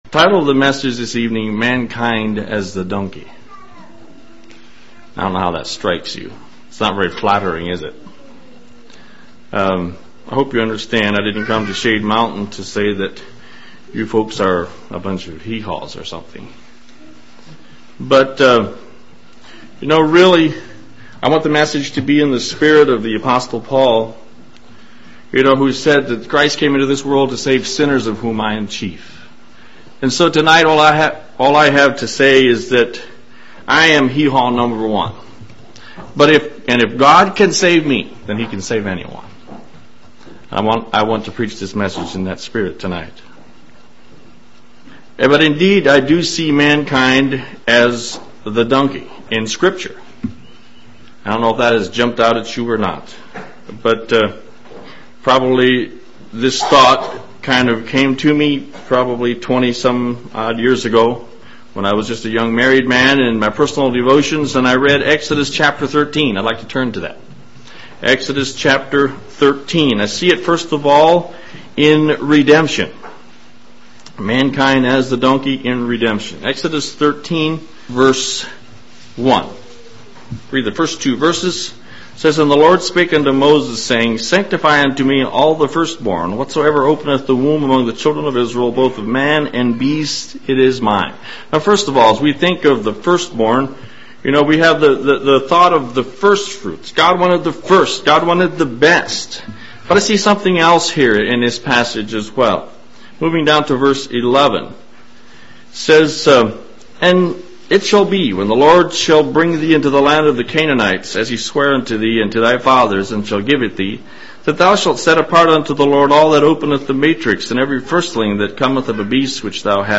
This sermon is about a unique parallel I have discovered in the Bible; people like donkeys.